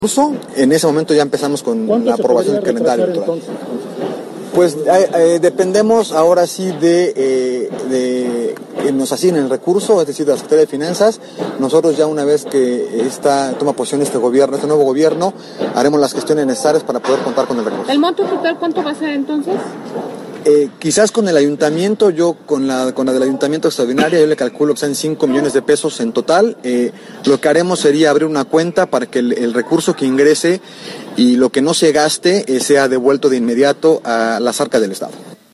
El presidente del Instituto Electoral del Estado (IEE), Jacinto Herrera Serrallonga, dijo que solicitarán a la Secretaría de Finanzas una ampliación presupuestal de 5 millones de pesos para organizar las elecciones extraordinarias en las juntas auxiliares que quedaron pendientes.
En entrevista, comentó que cuando la Secretaría de Finanzas apruebe el recurso se pondrá emitir una convocatoria para las elecciones extraordinarias en juntas auxiliares.